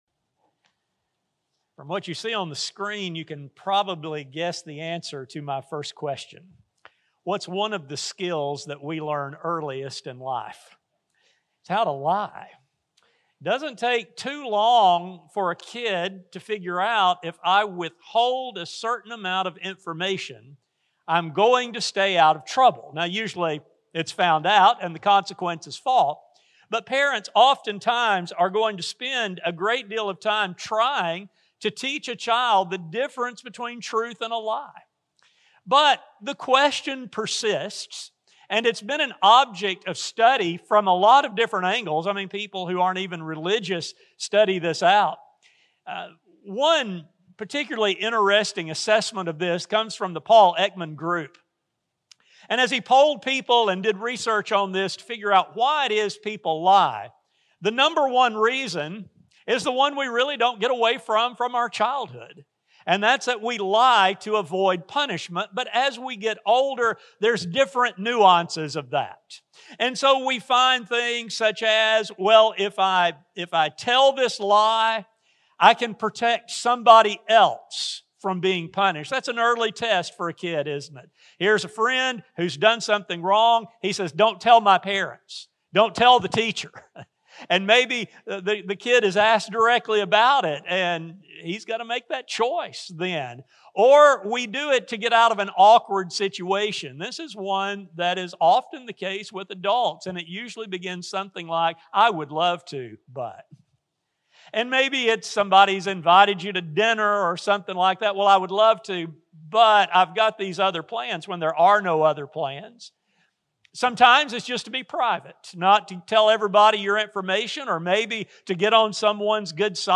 In recent times, situations have begun to occur that will challenge one’s honesty in ways once never considered. Because of this, Christians must be ready. A sermon recording